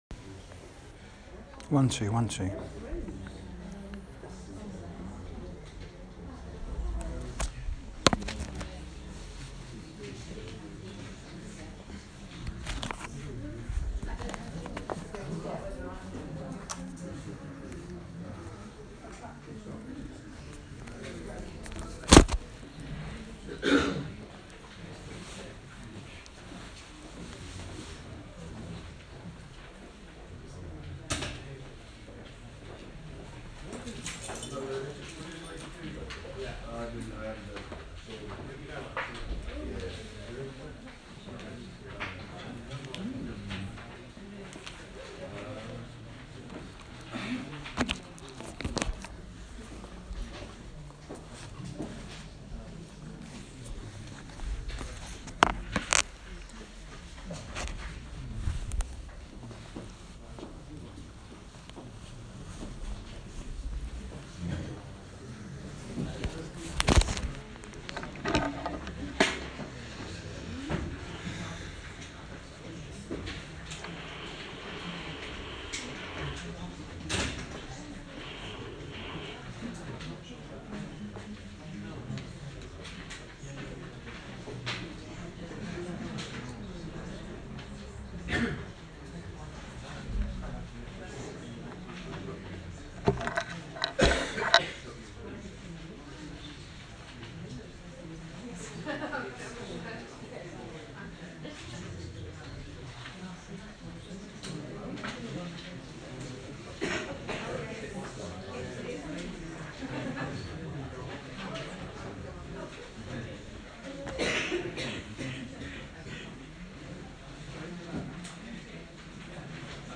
MCYM lecture on generational and intergenerational mission and ministry, September 2017